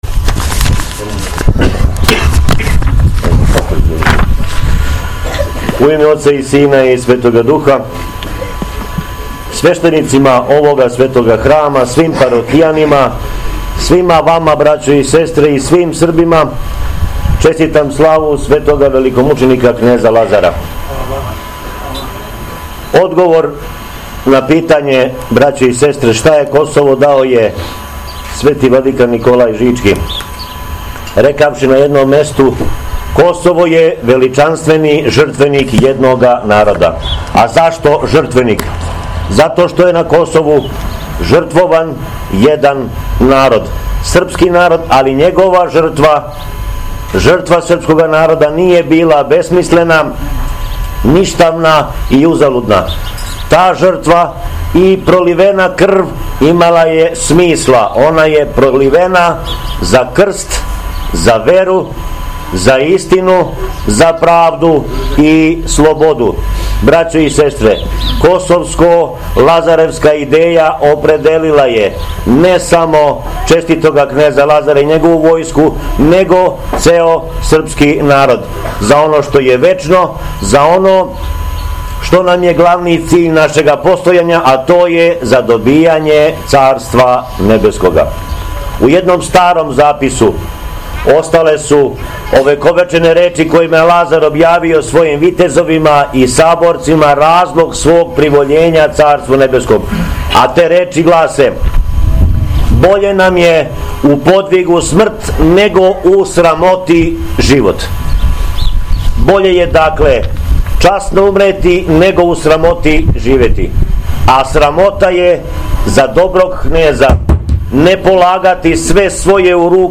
ХРАМОВНА СЛАВА У КРАГУЈЕВАЧКОМ НАСЕЉУ БЕЛОШЕВАЦ
Беседа